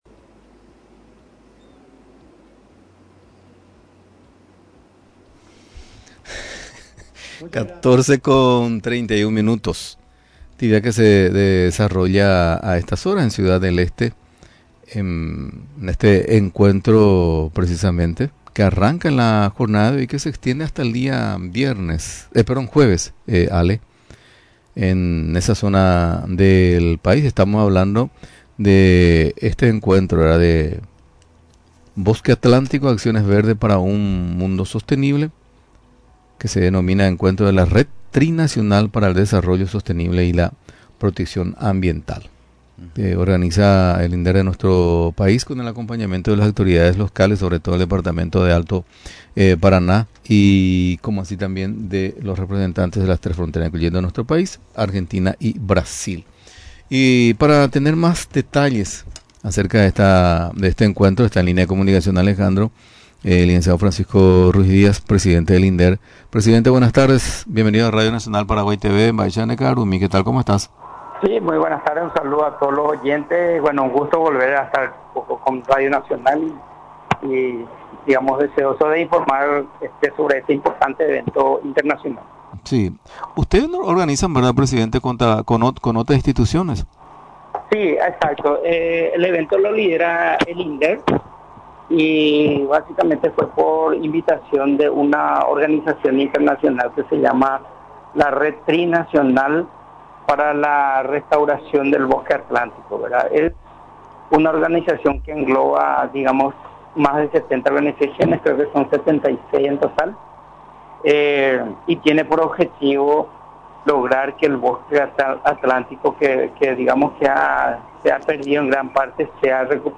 También dijo durante la entrevista en Radio Nacional del Paraguay que si no se realiza la restauración, iremos camino a la extinción del Bosque Atlántico.